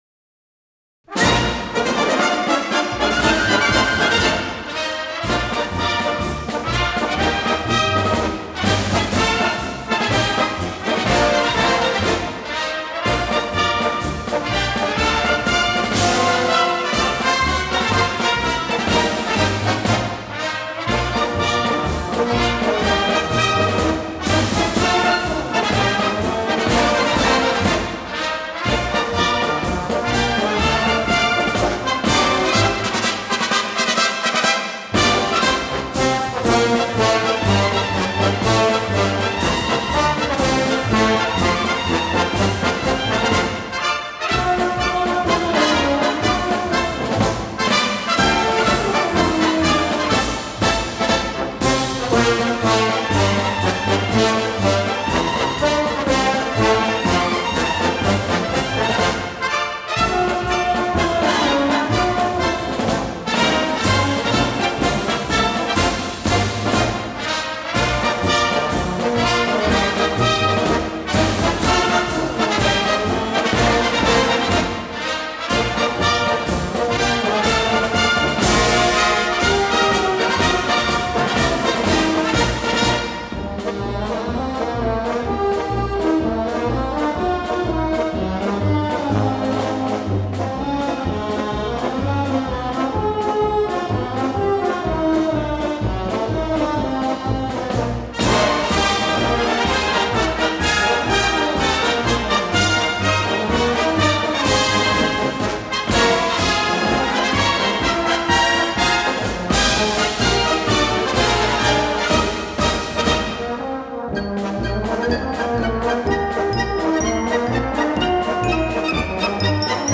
Главная / Computer & mobile / Мелодии / Марши